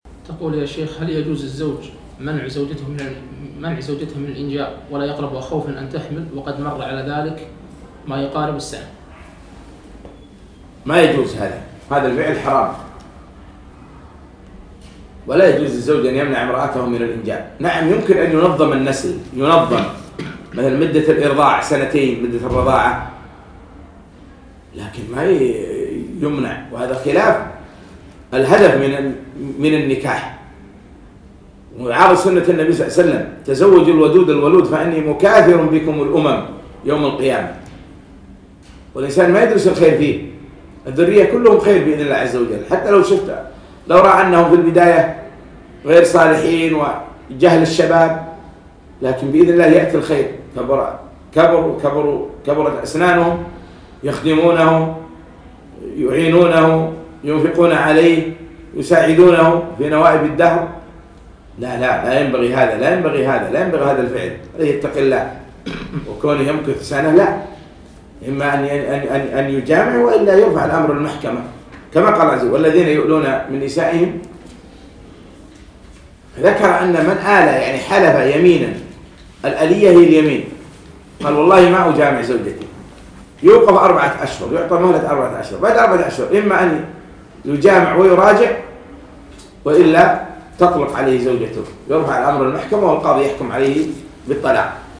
مقتطف من محاضرة فوائد وعبر من سورة الكهف المقامة في مركز إلهام البوشي بتاريخ 3 4 2017